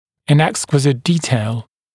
[ɪn ‘ekskwɪzɪt ‘diːteɪl][ин ‘экскуизит ‘ди:тэйл]в тончайших деталях (напр. о возможности изучить что-либо на снимке)